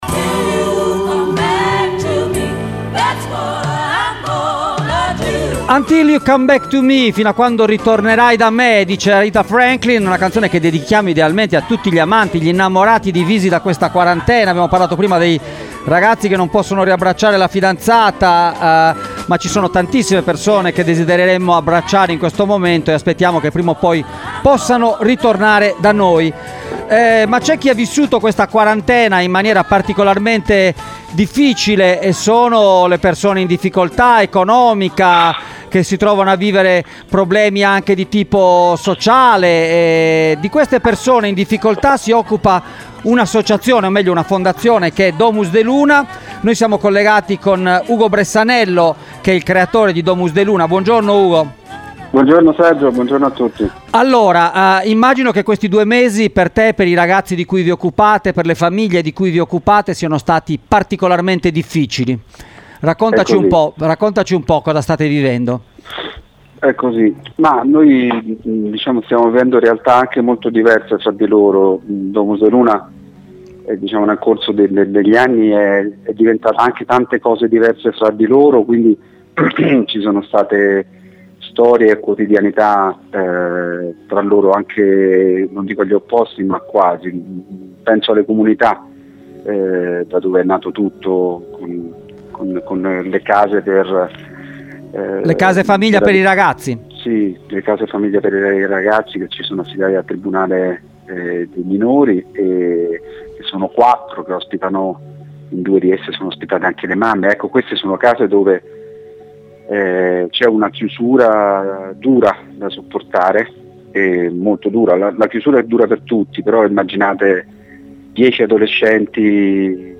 Ne abbiamo parlato in collegamento